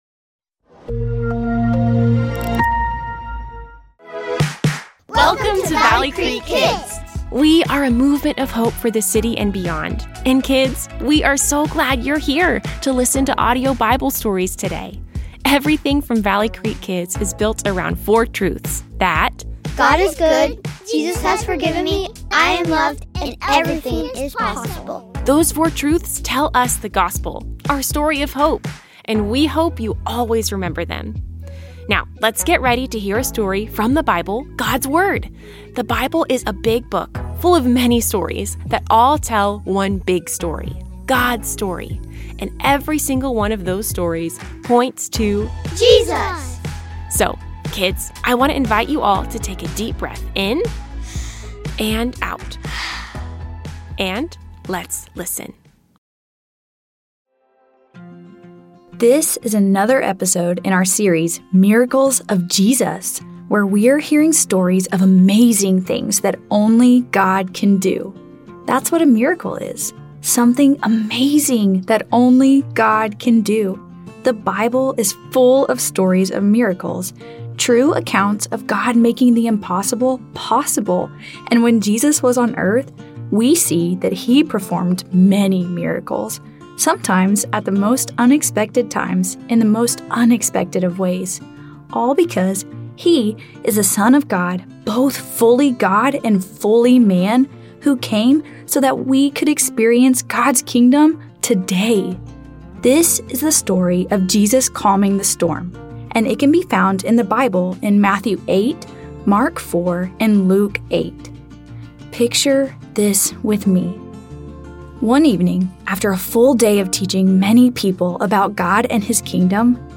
Kids Audio Bible Stories